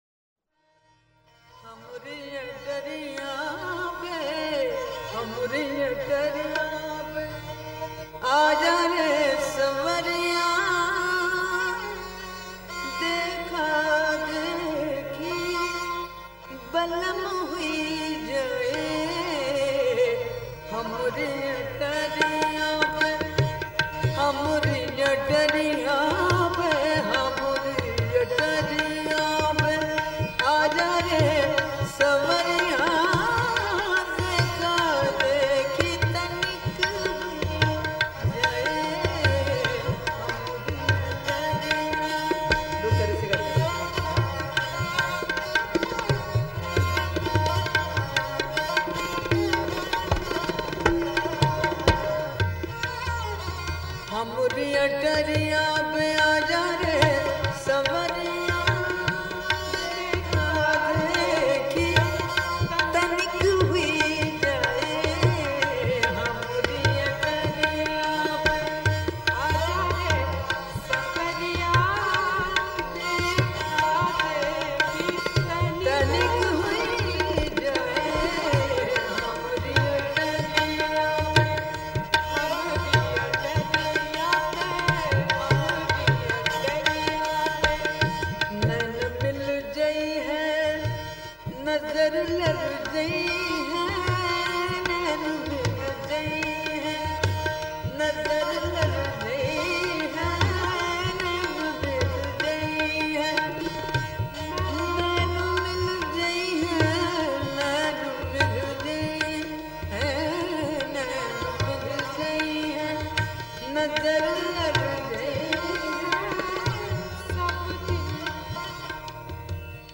Vocal
Thumri, Dadra, Tappa, Kajri, Hori, Jhoola etc.
Shobha Gurtu; Hori in Raga Kafi Sindhura;